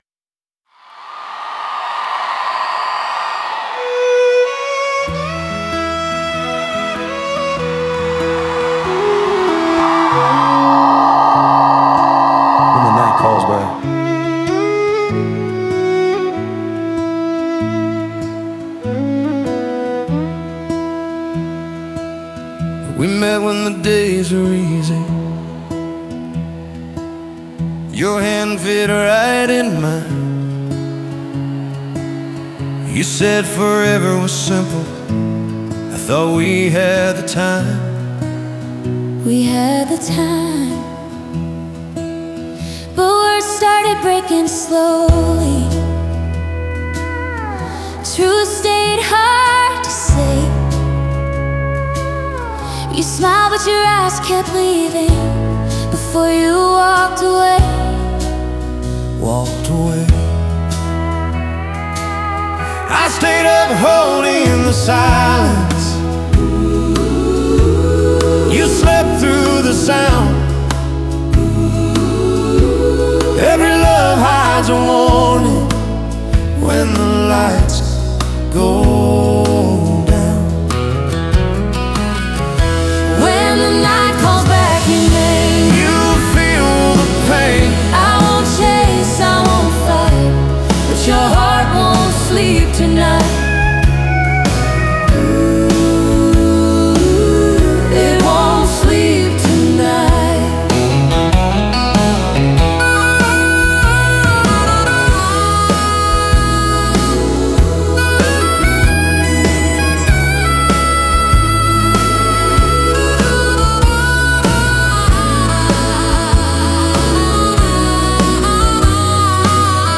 ライブ仕様では、観客の声が物語の一部となり、孤独な心情が「共有」へと変化する。